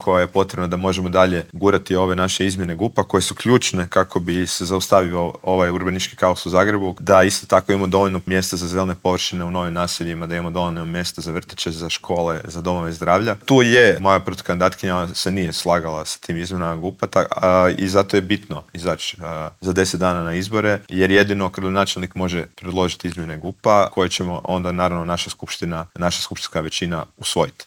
On je u prvom krugu lokalnih izbora osvojio 47,59 posto glasova Zagrepčana, a u Intervjuu tjedna Media servisa istaknuo je da je najvažnija većina u zagrebačkoj Gradskoj skupštini: